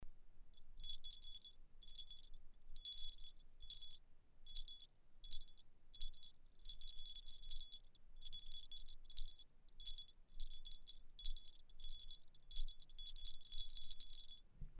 Tags: Avars Bells Great migration period Metallic idiophones Muscial instruments Music archaeology Original sound Pellet bells Psychoacoustics
Soundrecordings of original pellet bells and bells from the Avar and Carolingian Period kept in the Balaton Museum Keszthely, Hungary.
Sound of original pellet bell Esztergályhorváti-Alsóbárándpuszta grave 42 0.23 MB
Pellet_bell_Esztergalyhorvathi_Alsobarandpuszta_grave_42.MP3